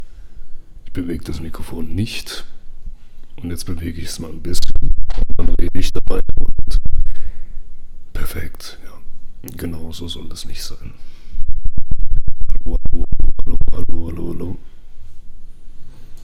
Behringer TM1: Merkwürdiges Unterbrechen des Signals beim Bewegen des Mikrofons
Selbe Störgeräusche..
Ich hänge mal eine Beispiel Aufnahme dran.
BehringerTM1_soundprobleme.mp3